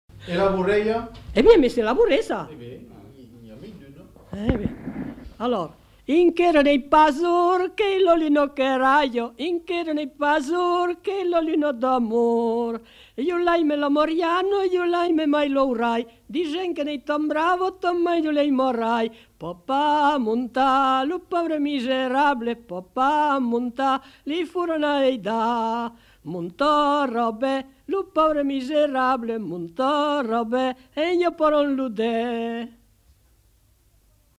Aire culturelle : Périgord
Genre : chant
Effectif : 1
Type de voix : voix de femme
Production du son : chanté
Danse : bourrée
Notes consultables : Enchaînement de plusieurs couplets à danser.